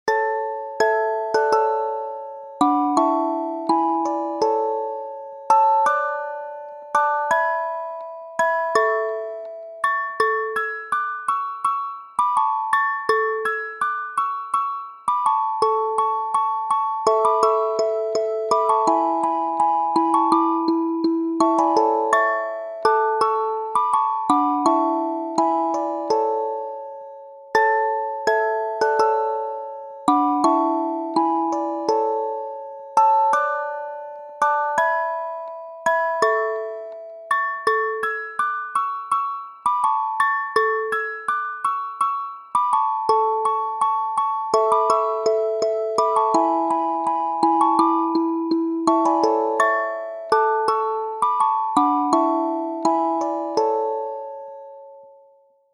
in Hymns by